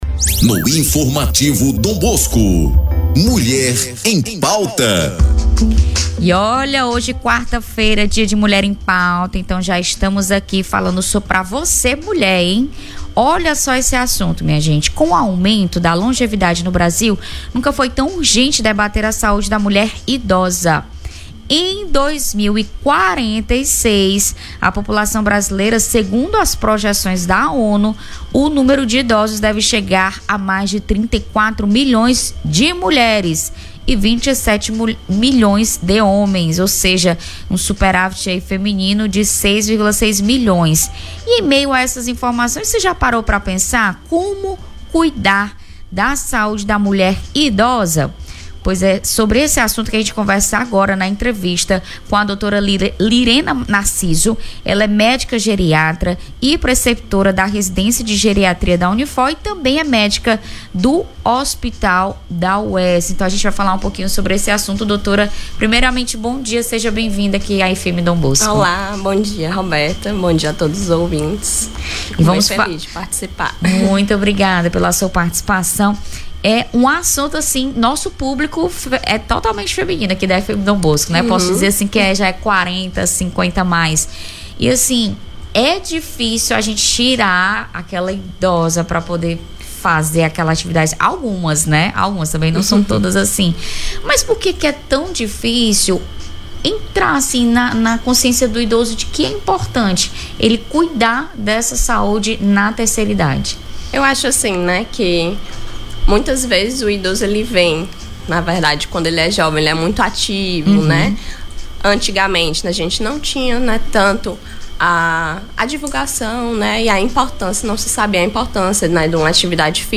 Envelhecimento da população acende alerta para a saúde da mulher idosa; confira entrevista
Durante a entrevista, a especialista aborda os principais desafios enfrentados pelas mulheres idosas e aponta caminhos para um envelhecimento saudável e ativo.